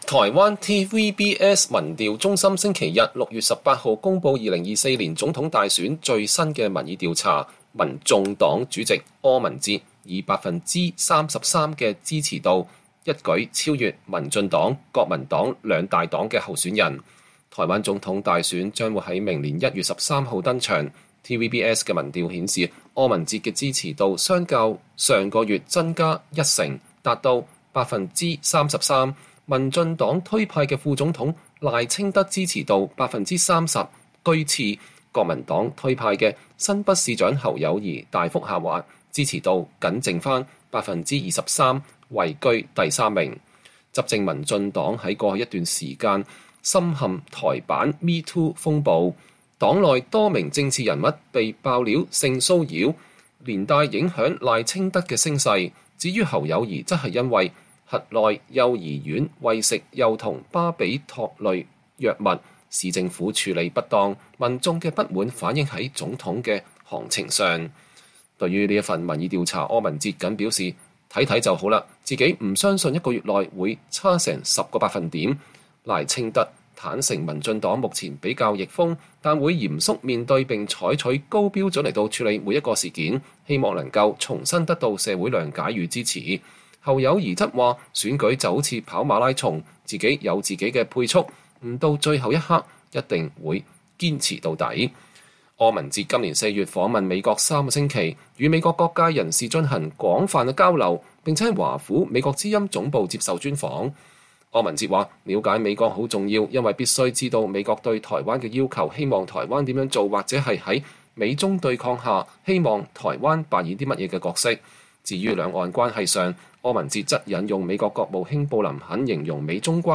台灣民眾黨主席柯文哲接受美國之音專訪